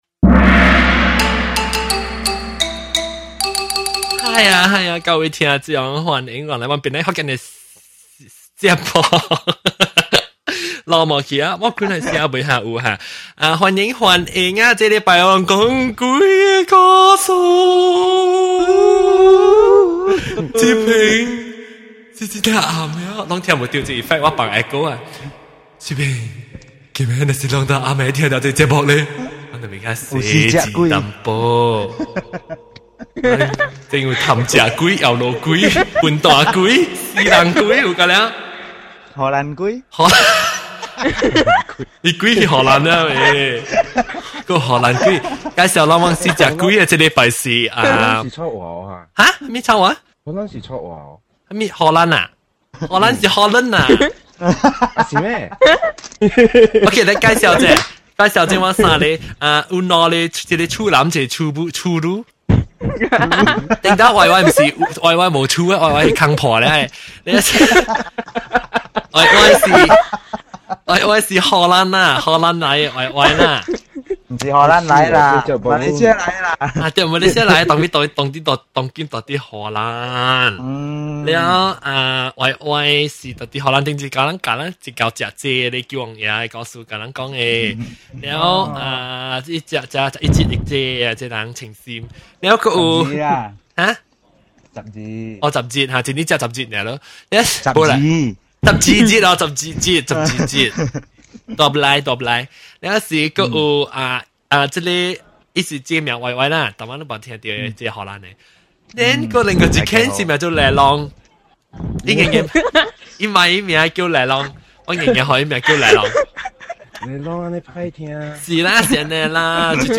We kick off the year 2007 with a brand new episode of ghost stories. We have a couple of new guests sharing their very creepy stories.